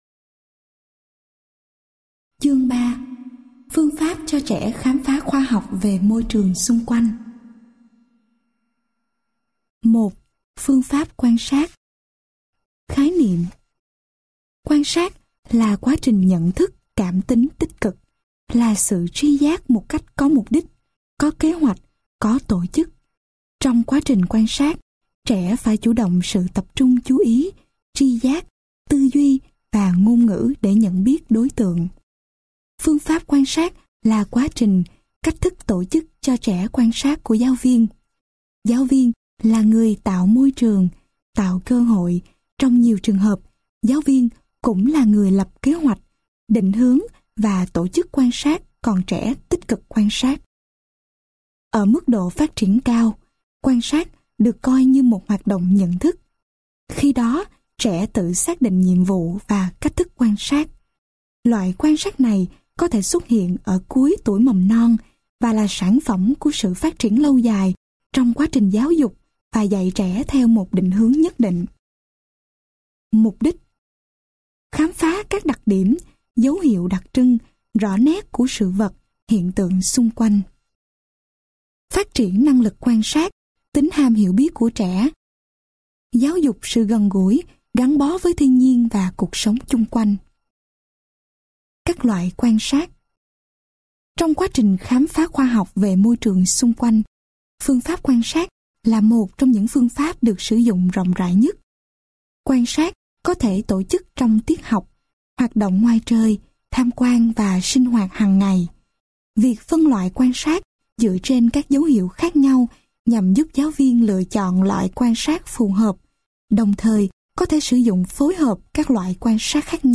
Sách nói Giáo trình Phương pháp cho trẻ mầm non khám phá khoa học về môi trường xung quanh - Sách Nói Online Hay
Giọng đọc: nhiều người đọc